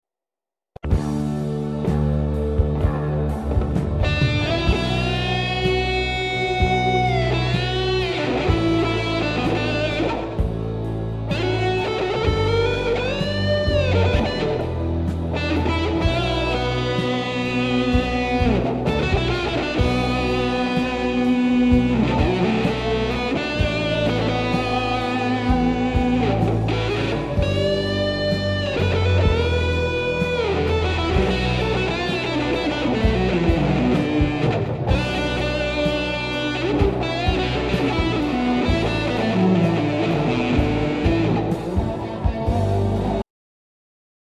Сыграл кусочик соло
Верха сильные :-(
То ли реверба надавил, то ли слишком много верхов убрал
Срезал в Рипере верха немного.
У меня гитара из ели :gg: поэтому визжит сильно